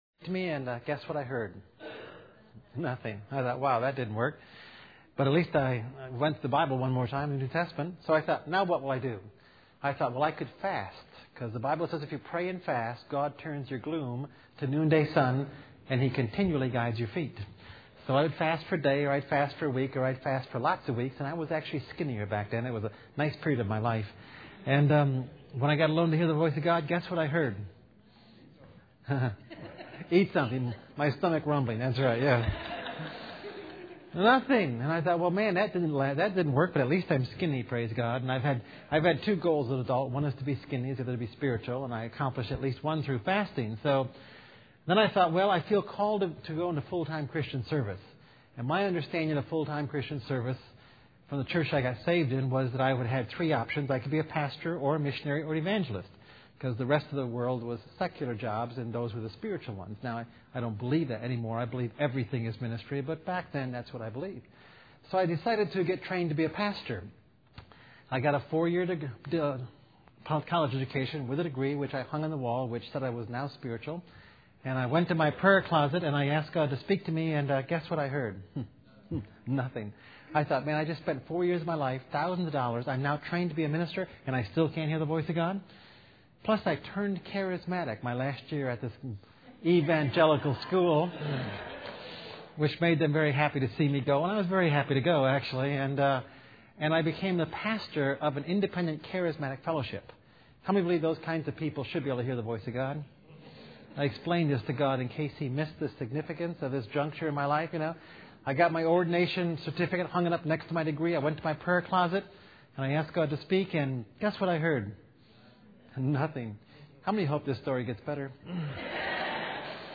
Communion with God: Saturday Seminar